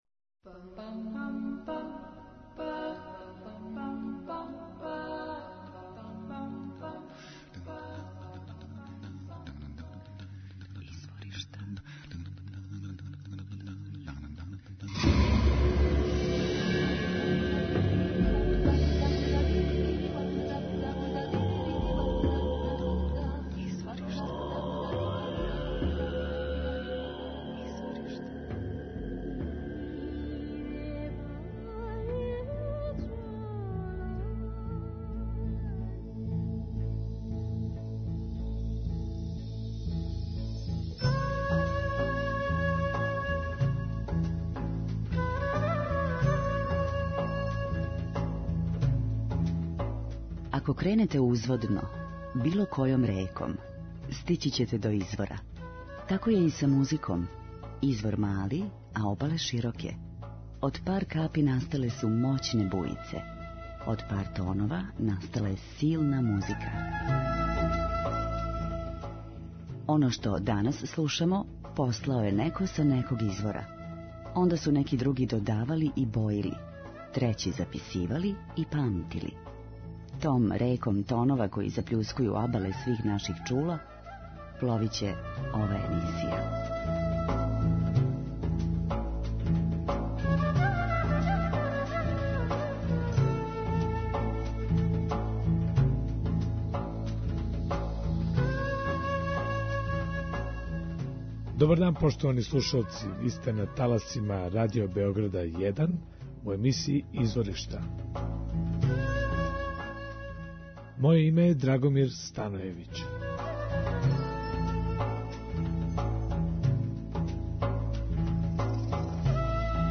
Енглески фјужн бенд